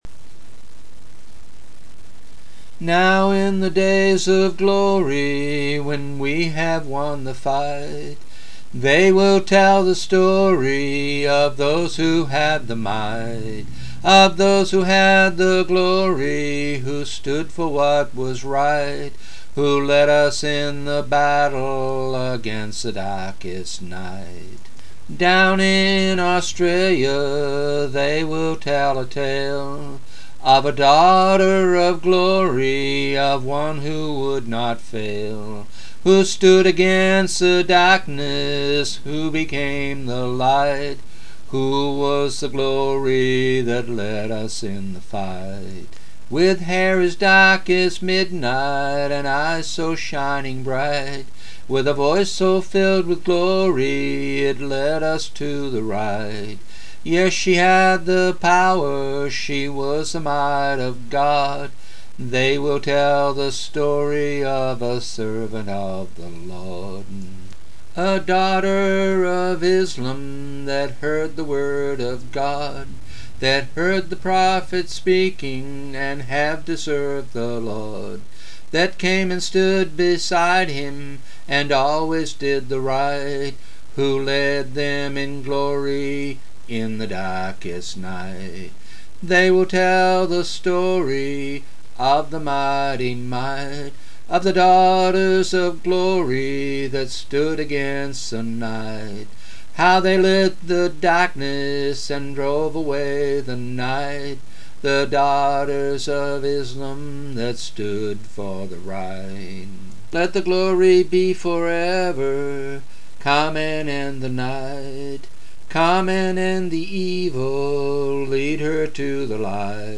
Finally, this morning he recorded a demo